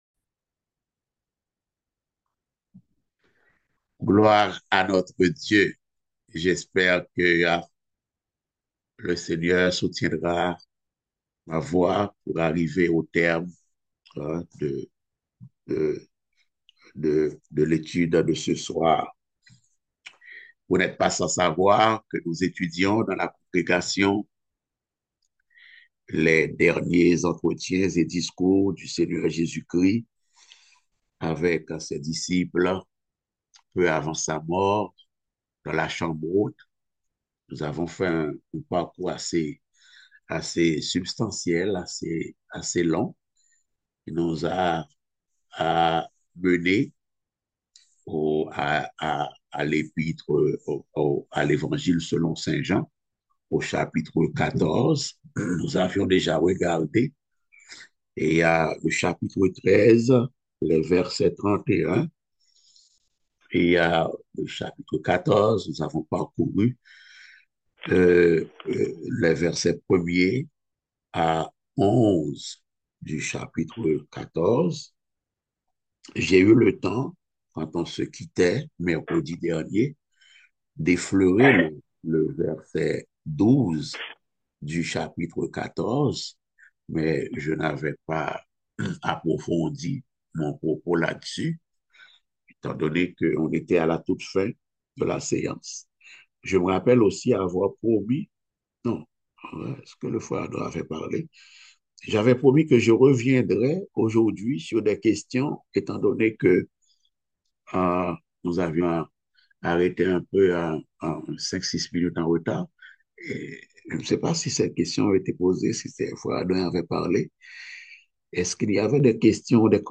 Passage: Jean 14.12-20 Type De Service: Études Bibliques « La dignité royale dans le lavement des pieds.